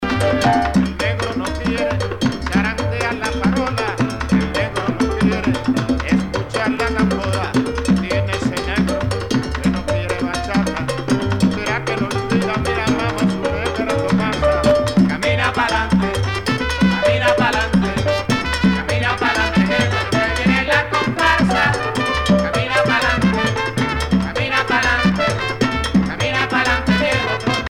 danse : conga
Pièce musicale éditée